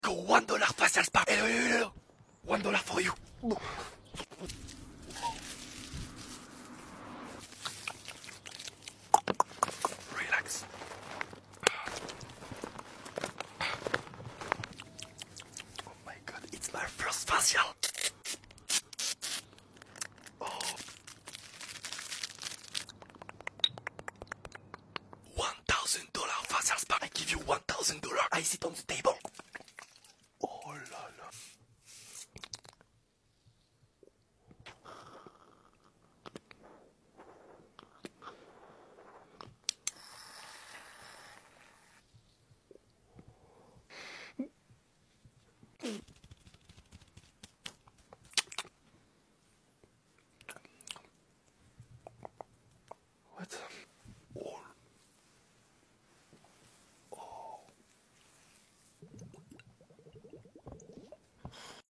ASMR $1 VS $10000 FACIAL sound effects free download